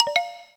gamesave.ogg